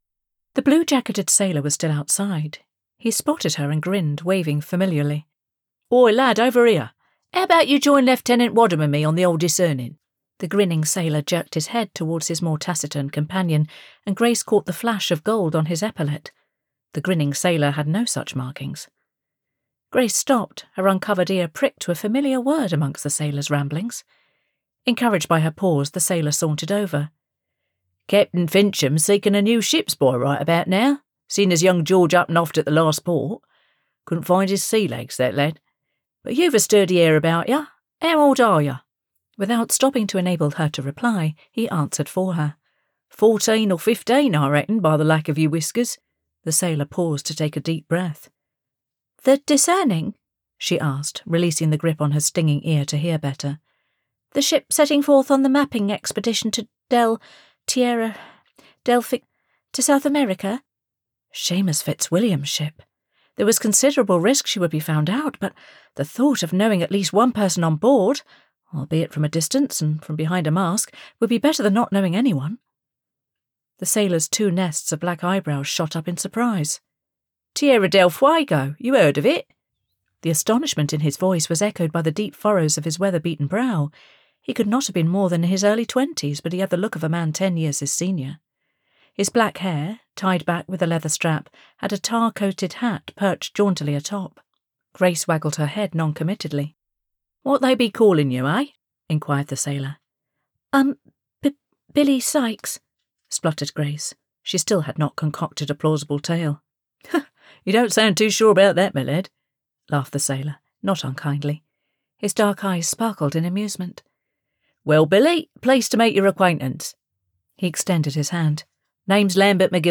Genre: Historical Women’s Fiction Audiobook